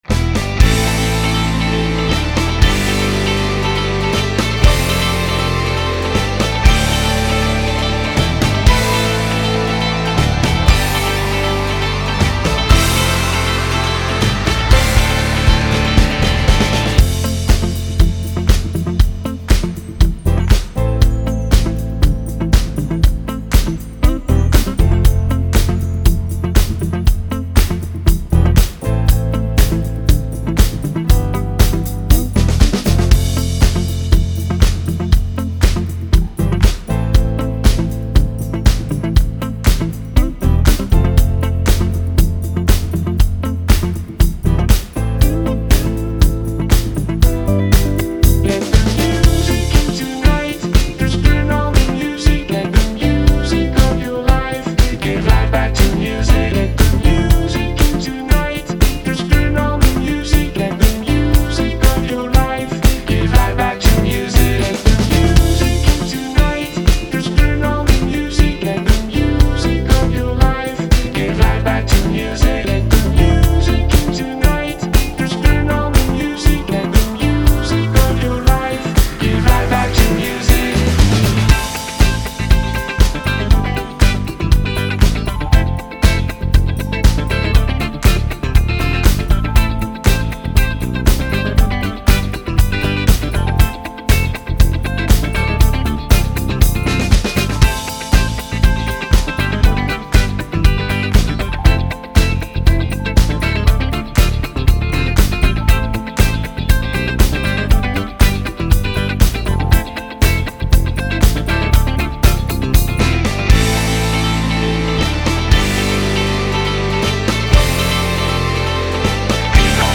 Genre : Dance